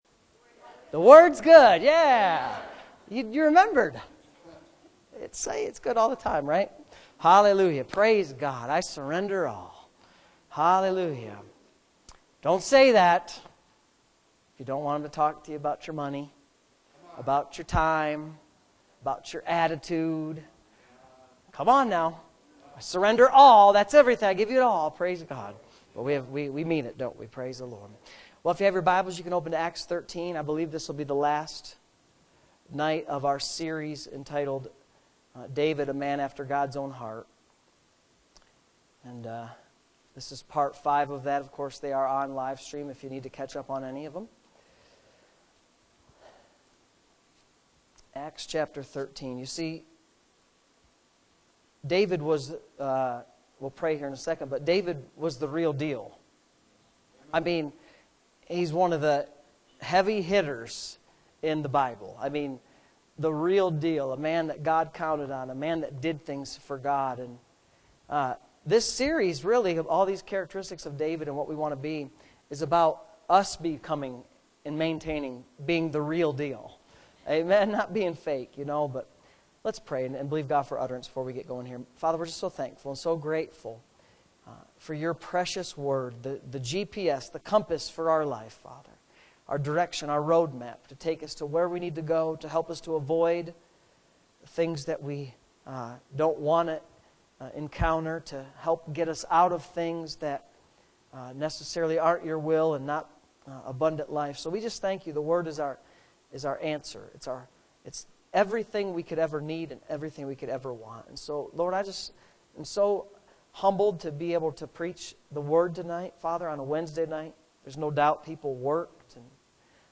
Wednesday Evening Services